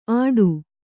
♪ āḍu